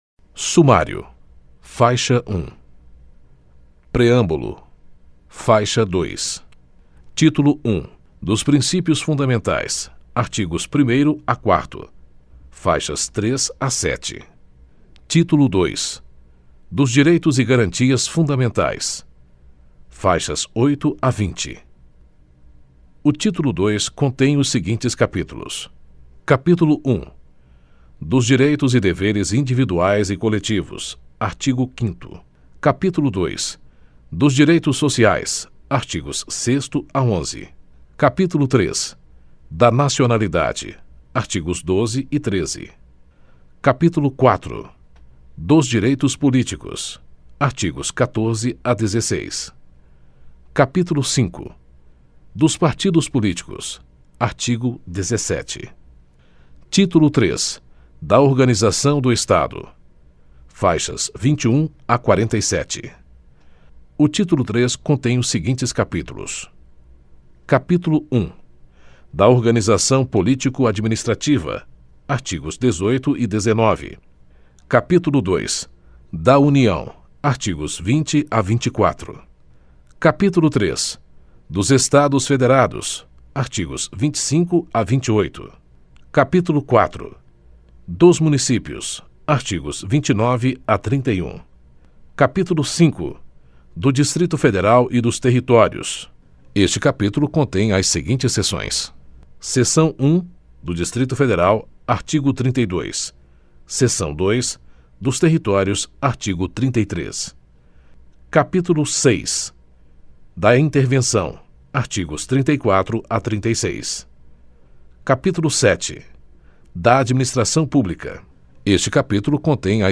Fonte: Áudio Livro Edições Câmara dos Deputados Você sabia que o site Prova da Ordem possui legislações em áudio para que você possa escutar quando e onde quiser?